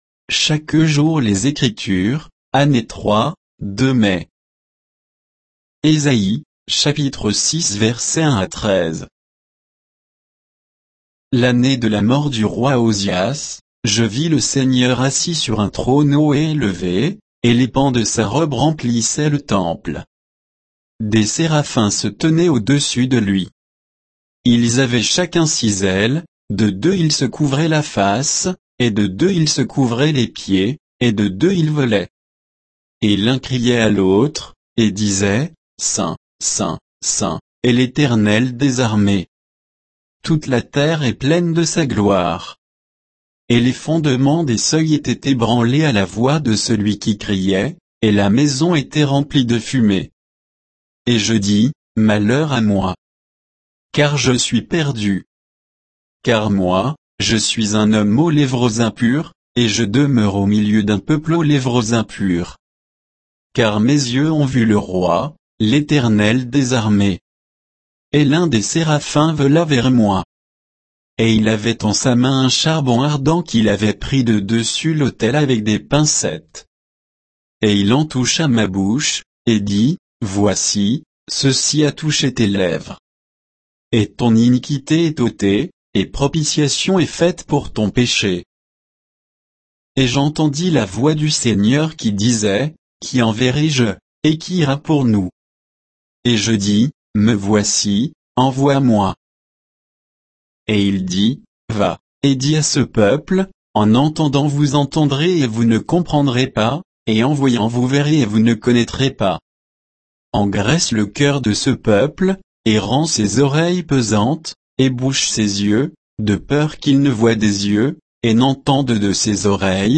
Méditation quoditienne de Chaque jour les Écritures sur Ésaïe 6, 1 à 13